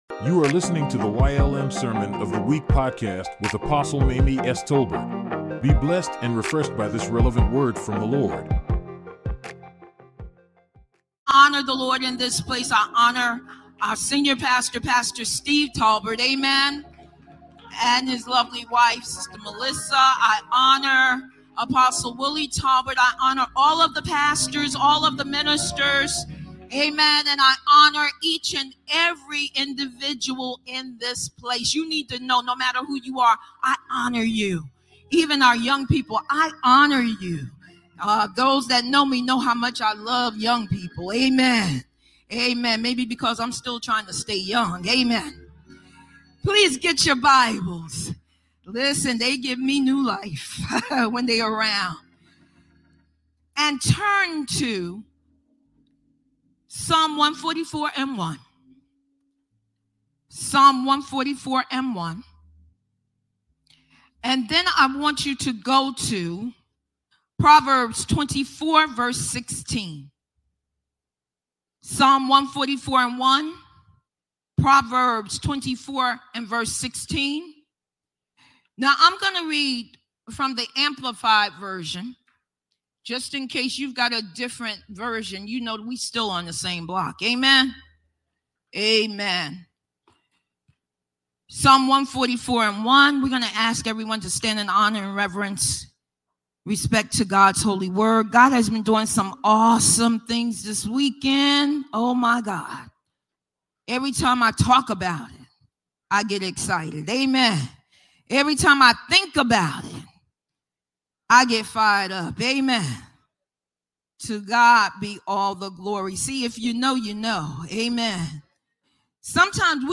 YLM Sermon of the Week | Yes Lord' Ministries